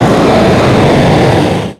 Cri de Groudon dans Pokémon X et Y.